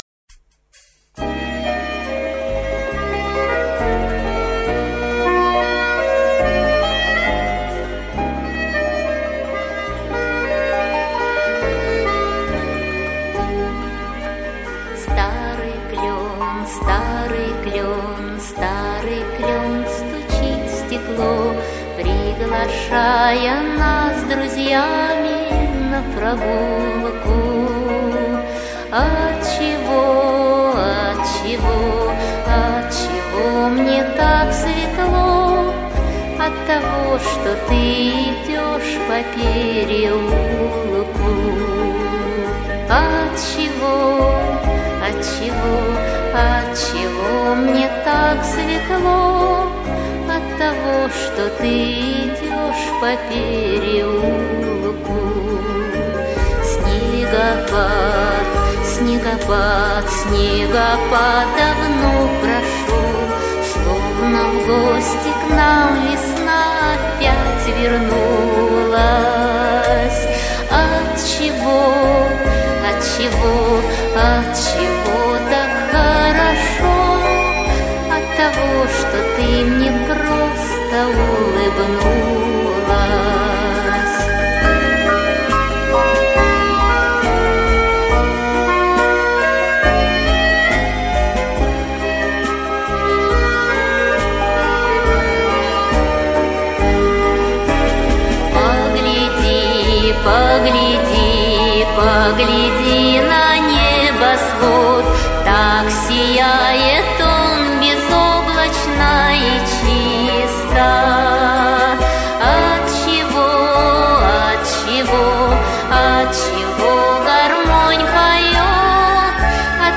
Жанр: Поп-фолк